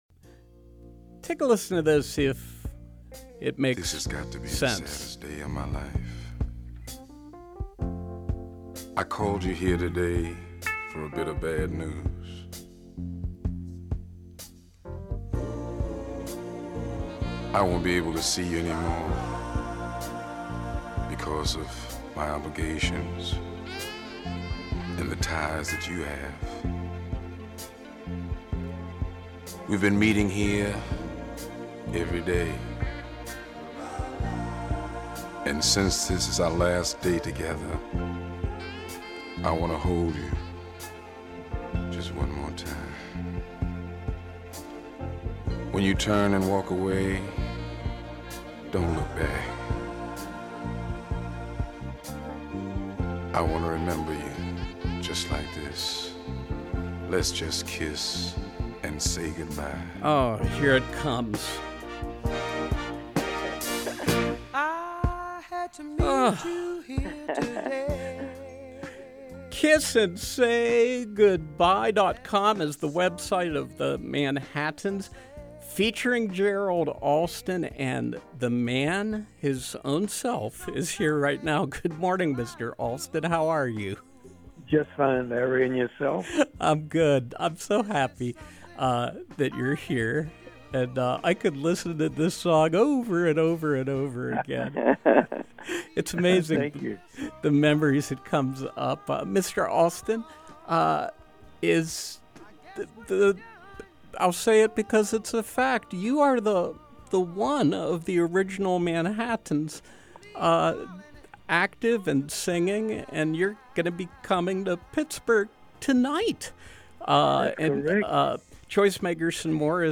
Interview: The Manhattans, Gerald Alston